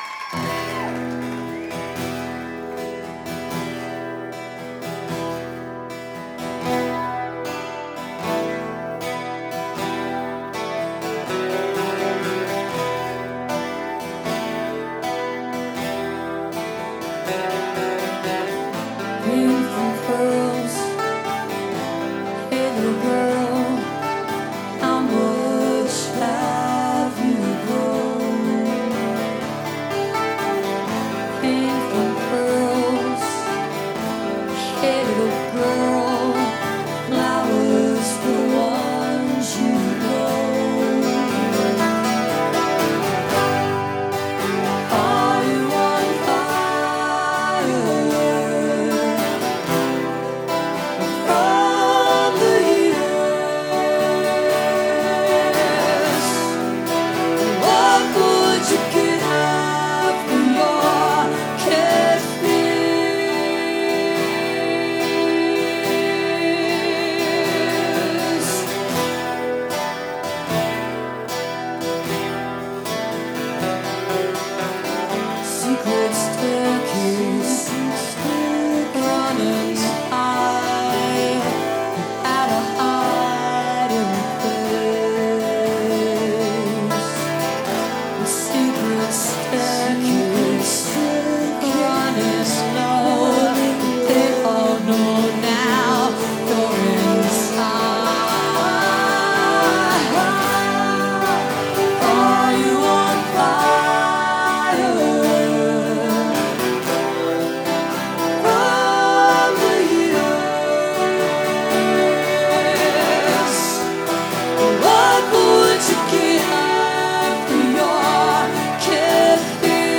1993-08-21 autzen stadium - eugene, oregon